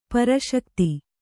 ♪ para śakti